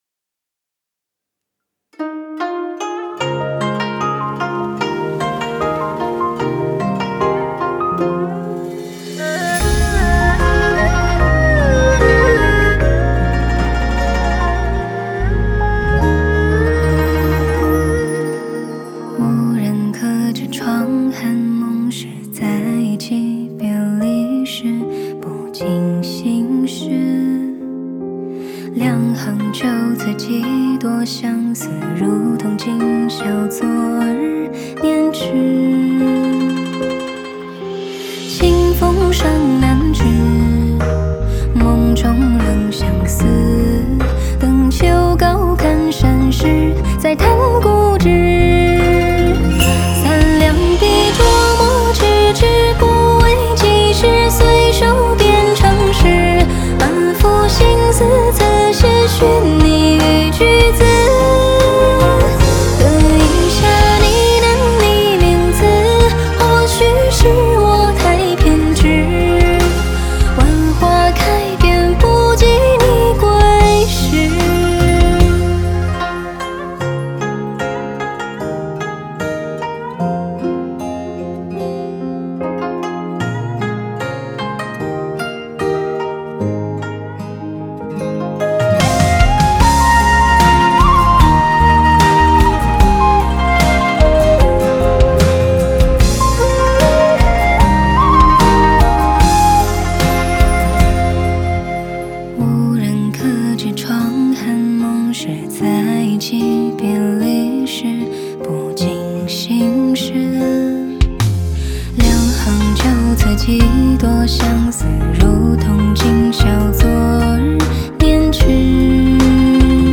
Ps：在线试听为压缩音质节选，体验无损音质请下载完整版
吉他
二胡
古筝
箫